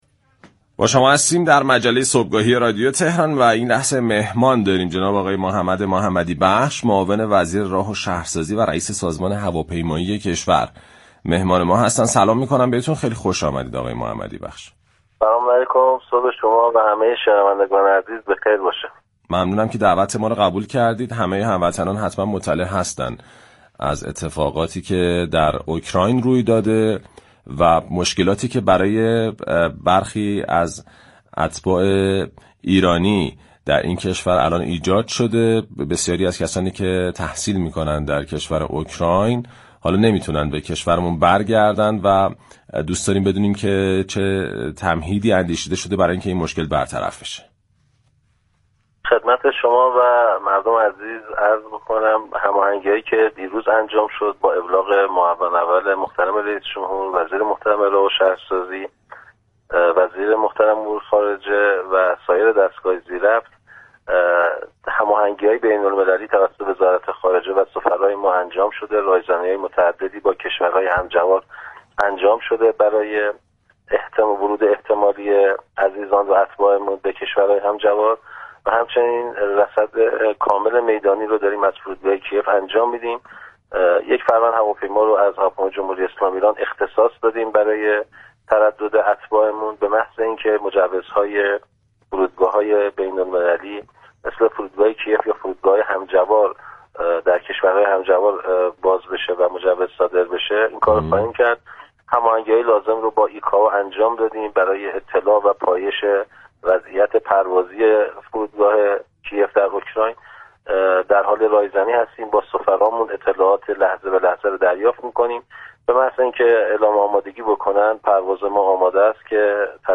به گزارش پایگاه اطلاع رسانی رادیو تهران، محمد محمدی‌بخش معاون وزیر راه و شهرسازی و رئیس سازمان هواپیمایی كشور در گفتگو با پارك شهر رادیو تهران درباره مشكلات اتباع ایرانی مقیم اوكراین گفت: هماهنگی‌های بین‌المللی لازم توسط وزارت خارجه و رایزنی‌های متعدد با كشورهای همجوار اوكراین به منظور ورود اتباع ایرانی به این كشورها انجام شده است.